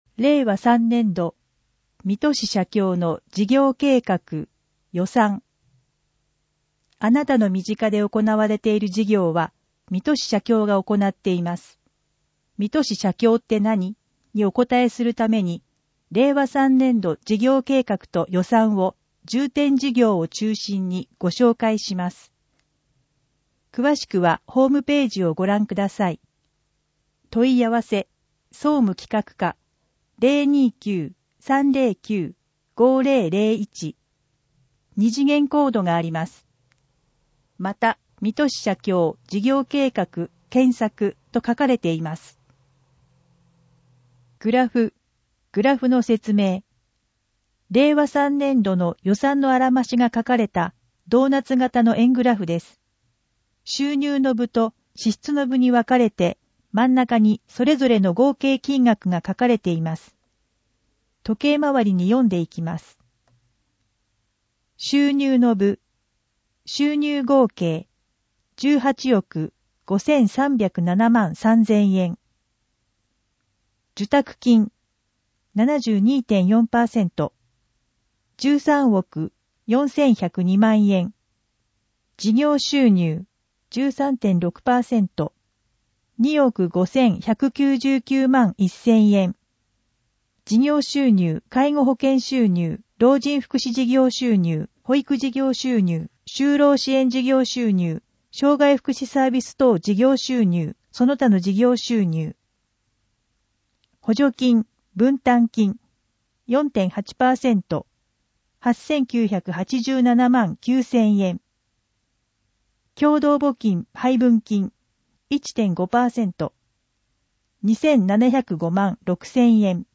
音声ガイド
（音声データ作成：音訳ボランティア「こだま」）